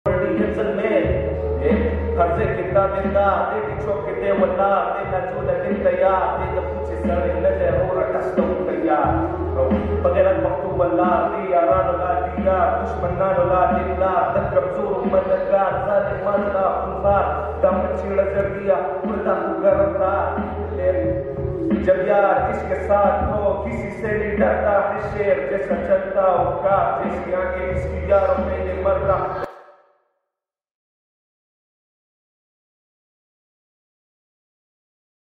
Another performance of RAP Song